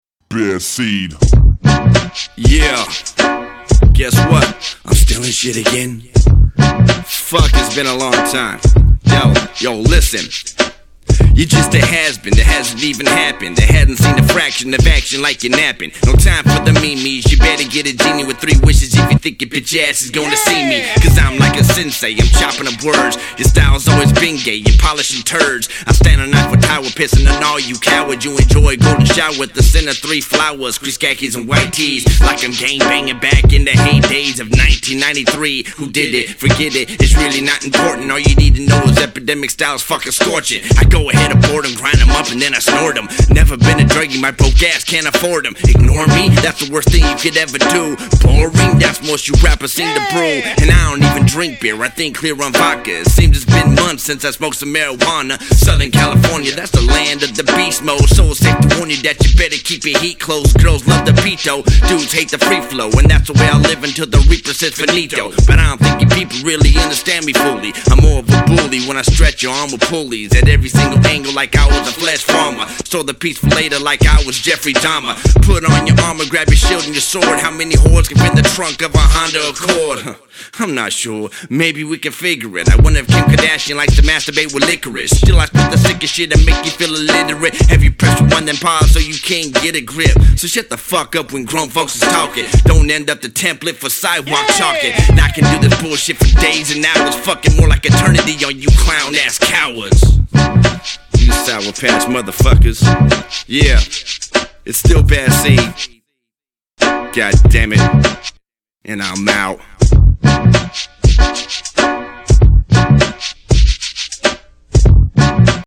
freestyle hip hop
rap westcoast